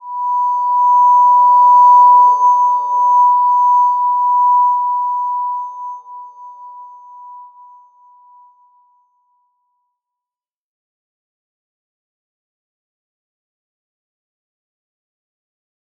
Slow-Distant-Chime-B5-mf.wav